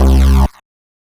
Synth Stab 16 (C).wav